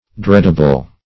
Dreadable \Dread"a*ble\, a.
dreadable.mp3